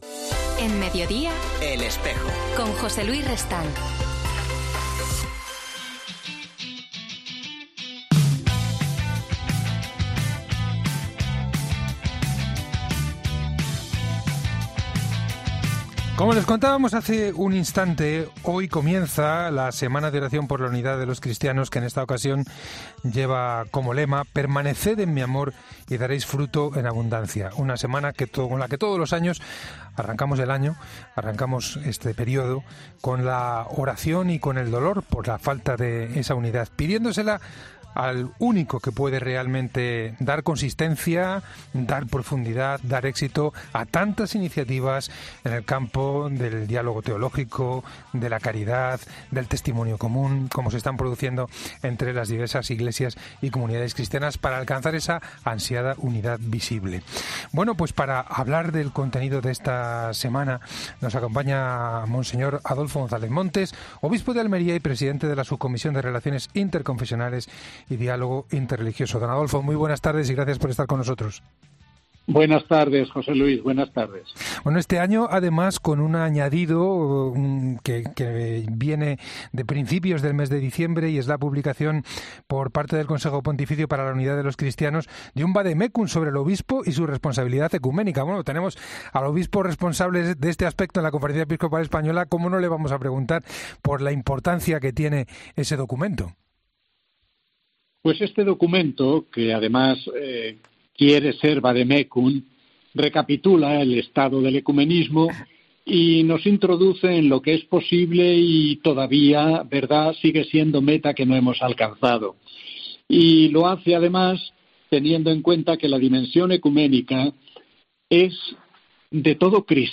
Para hablar de todo ello, ha pasado por 'El Espejo' de la Cadena COPE monseñor Adolfo González Montes, obispo de Almería y presidente de la Subcomisión Episcopal de Relaciones Interconfesionales y Diálogo Interreligioso.